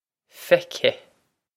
Feicthe Fec-heh
Pronunciation for how to say
This is an approximate phonetic pronunciation of the phrase.